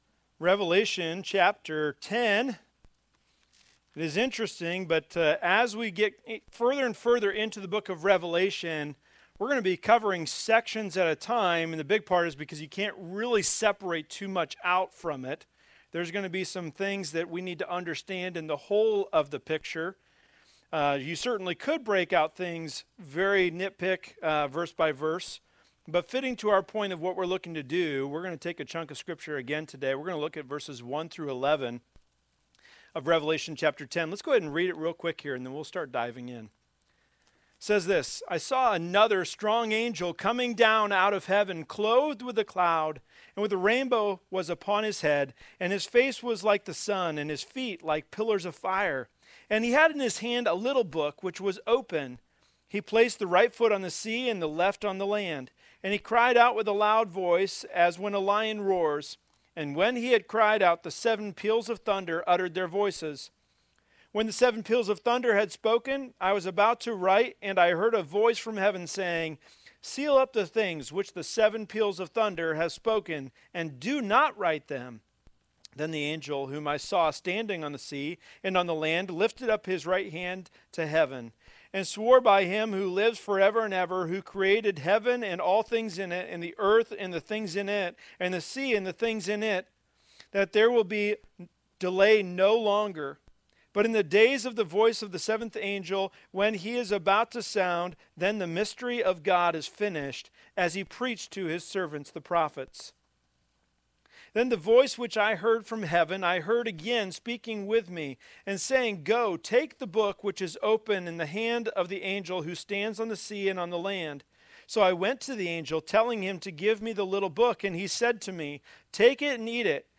Services